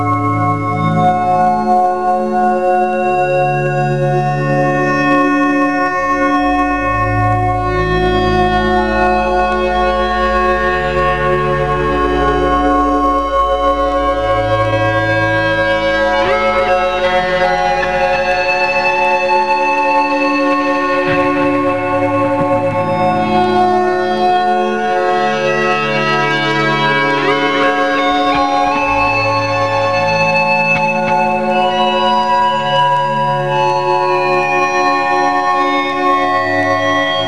音楽的にも幅広くアンビエント、エスニック、プログレ風
(STICK,VOICE)
(GUITAR,GUITAR SYN)
(KEY,STICK,VOICE)